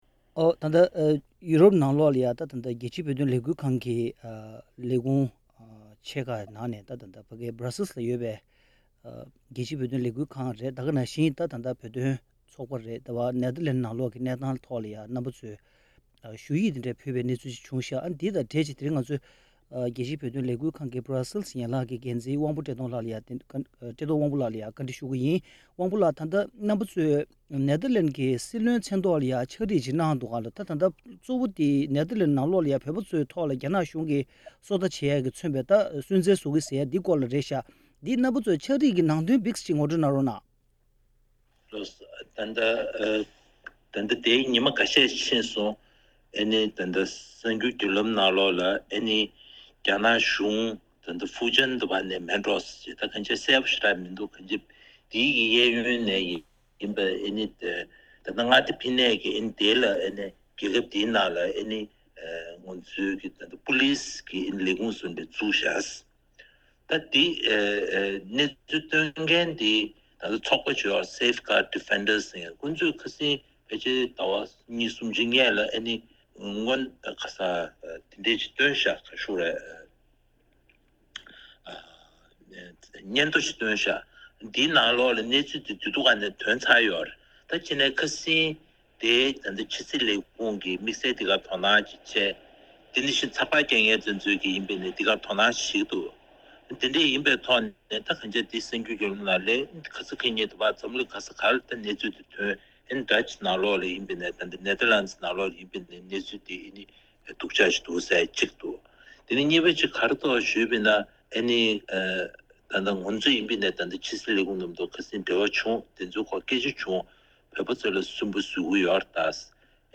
བཀའ་དྲི་ཞུས་པའི་ལས་རིམ་ཞིག་གསན་རོགས་གནང་།